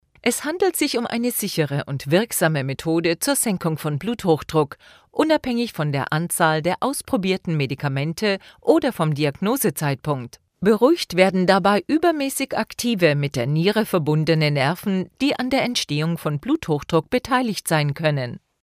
locutora alemana de curso de formación